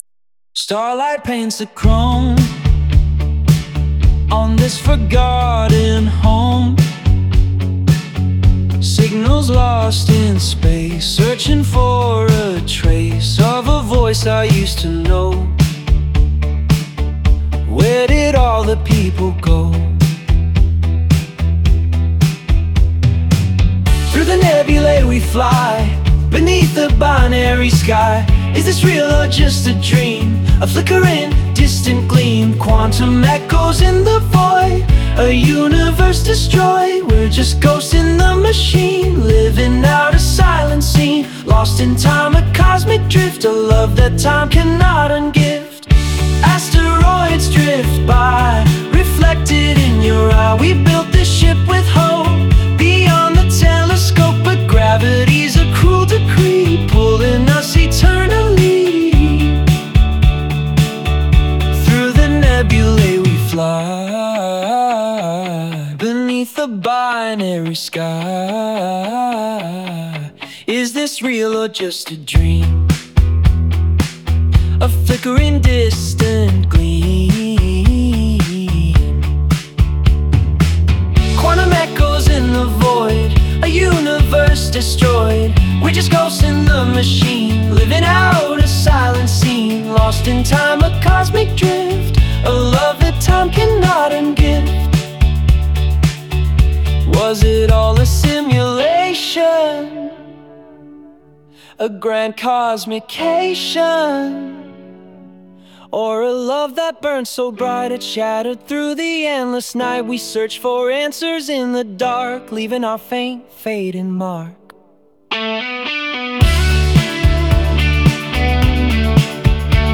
"Sci-fi indie rock"